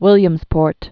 (wĭlyəmz-pôrt)